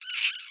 squeak.mp3